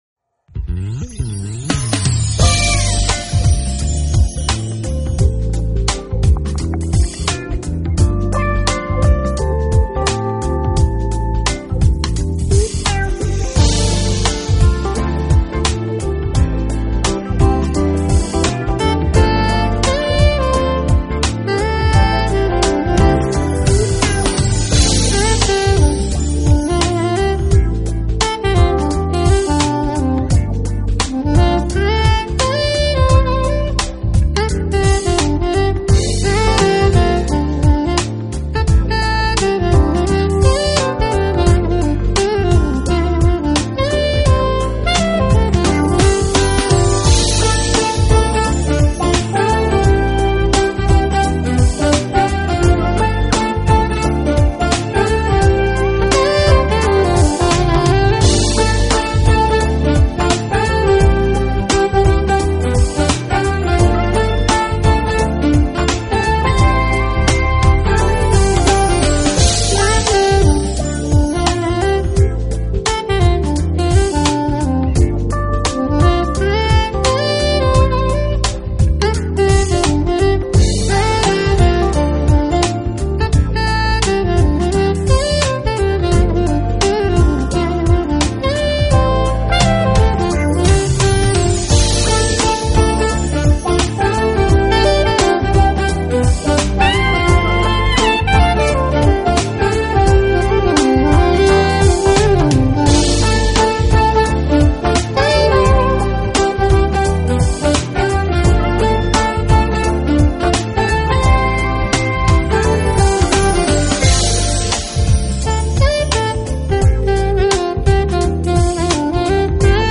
音乐类型: Smooth Jazz